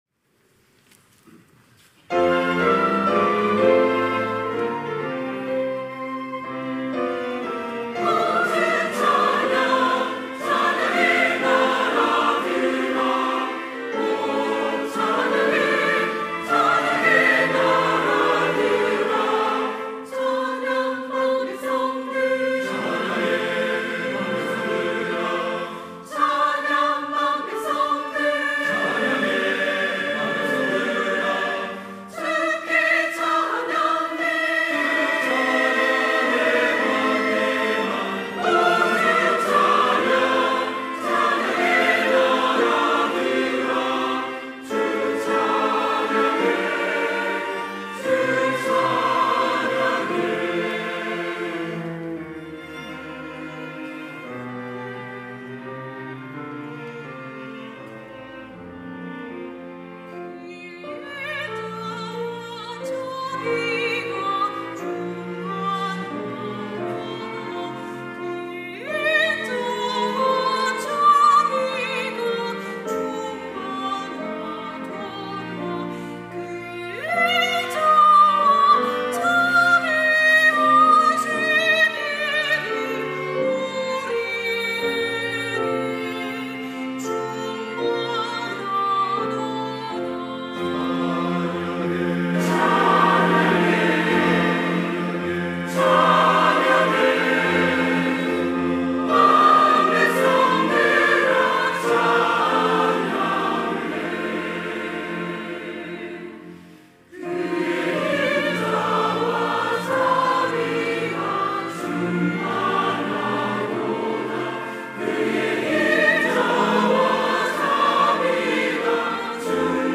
호산나(주일3부) - 오 주를 찬양해
찬양대